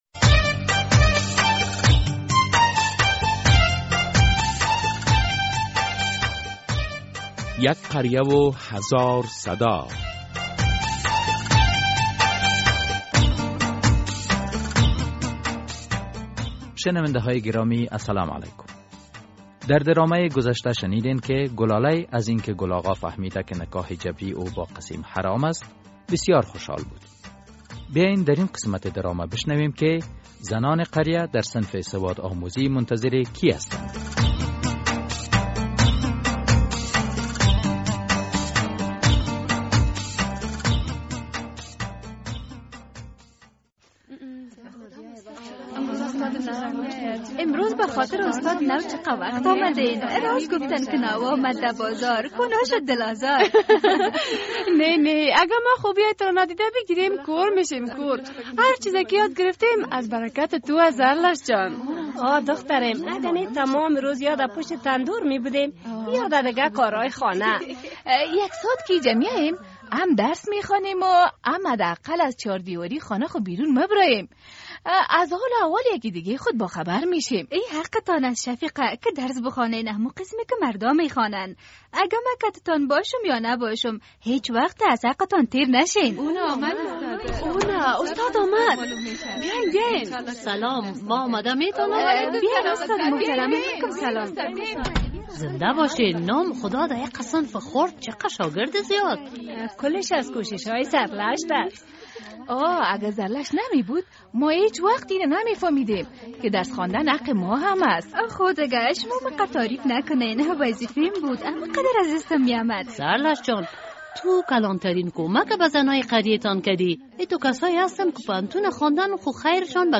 فکر می کنید مانع شدن عروس از خانه پدرش خشونت است؟ در ۱۸۷مین قسمت درامه یک قریه هزار صدا به این موضوع پرداخته شده است ...